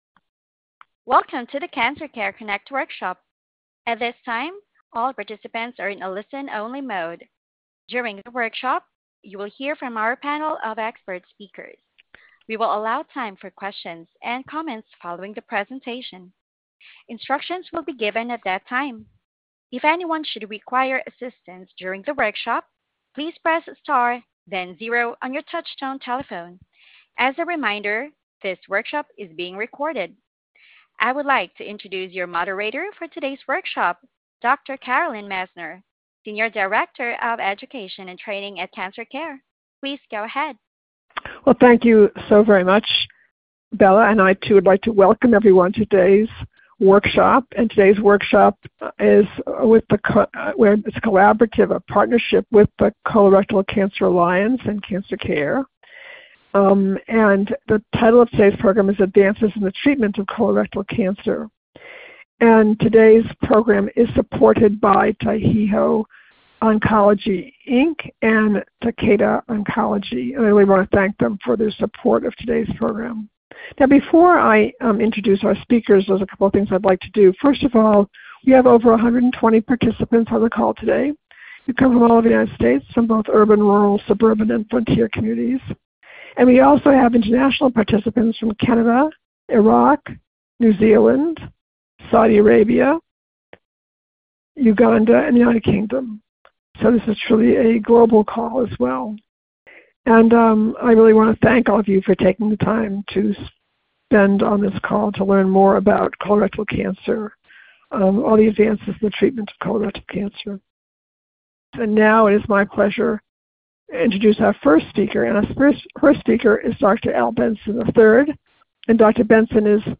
Questions for Our Panel of Experts